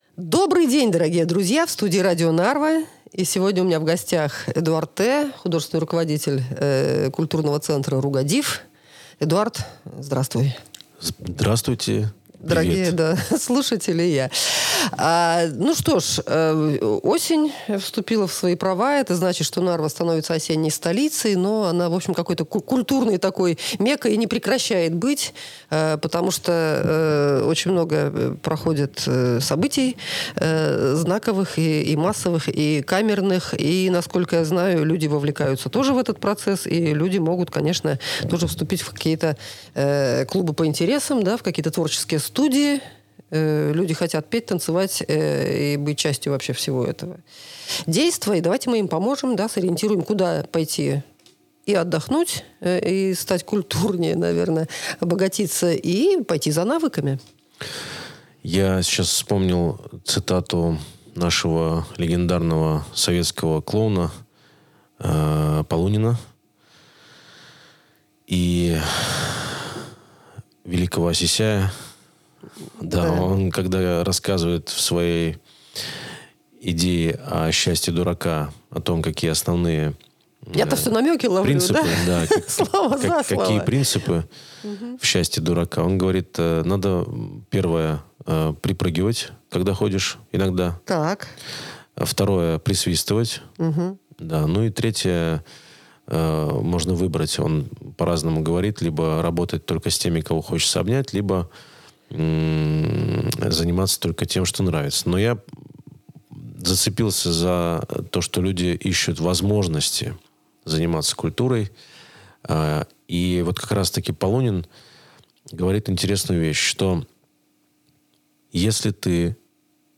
Подробности - в интервью.